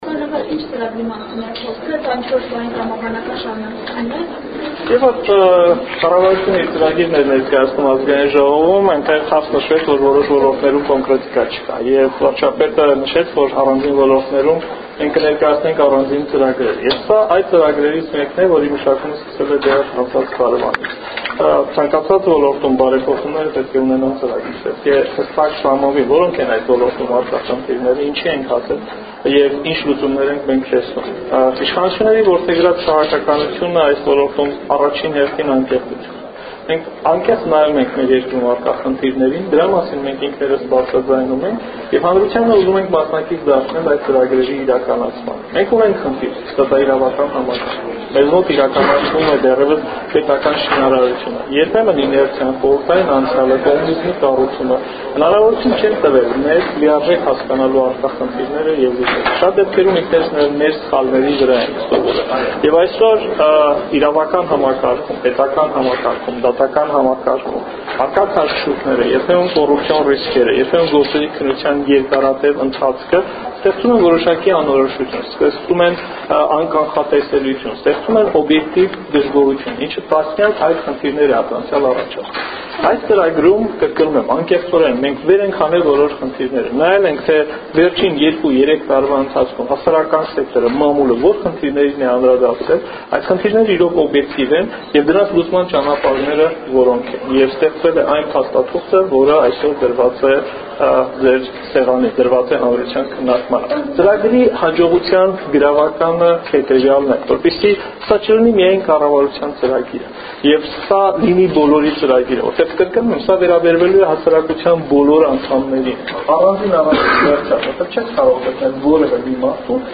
Նախարարի ամբողջական ճեպազրույցը լրագրողների հետ՝ ձայնագրությունում:naxarar